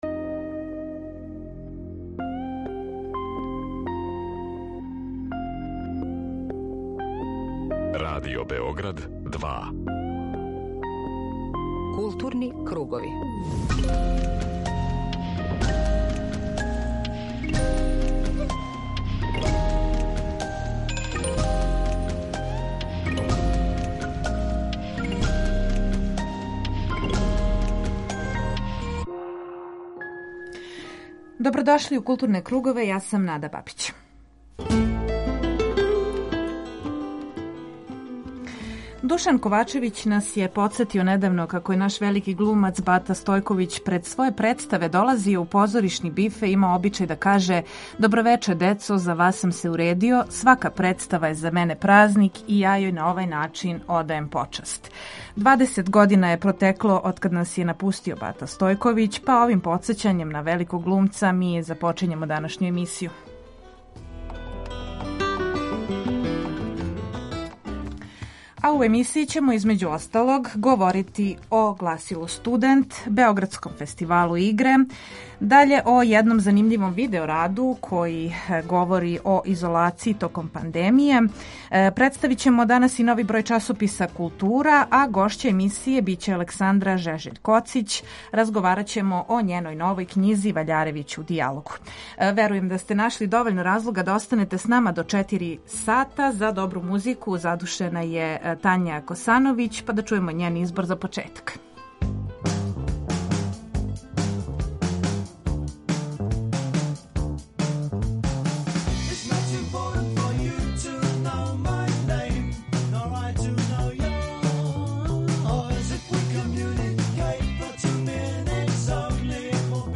Група аутора Централна културно-уметничка емисија Радио Београда 2.